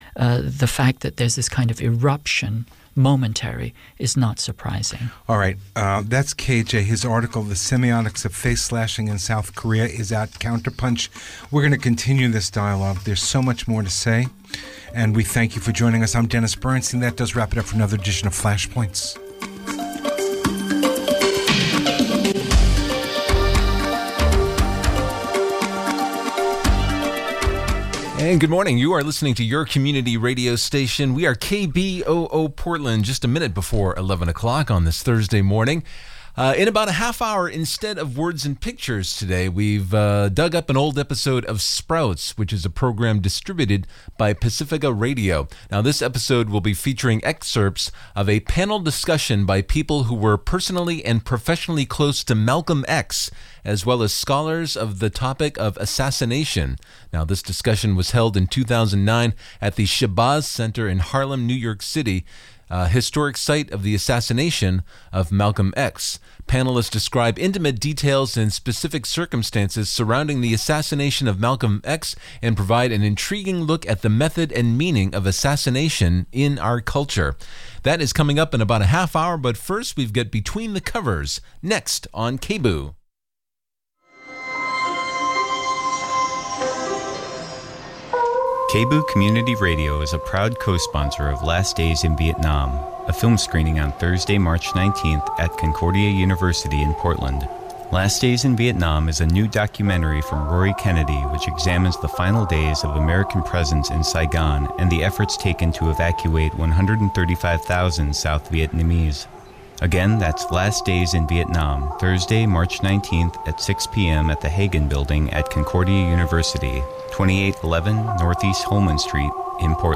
Every third Thursday from 11:00 AM to 12:00 PM A weekly show featuring interviews with locally and nationally known authors of both fiction and non-fiction.